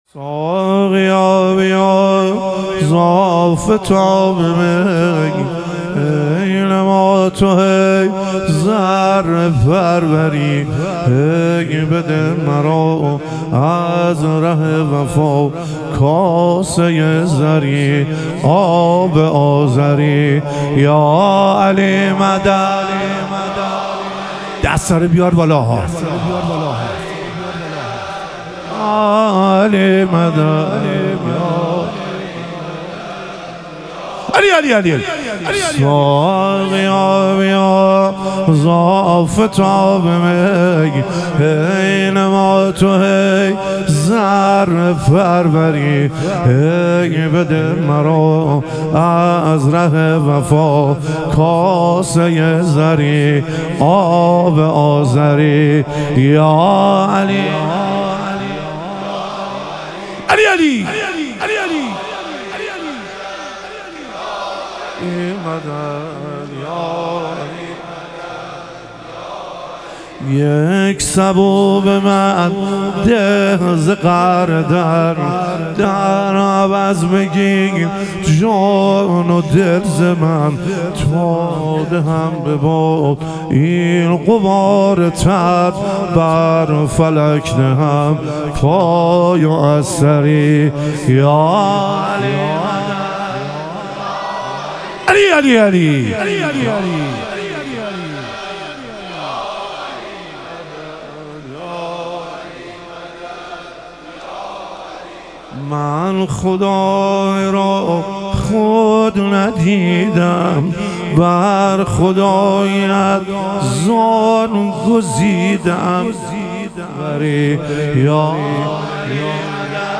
ظهور وجود مقدس حضرت علی اکبر علیه السلام - مدح و رجز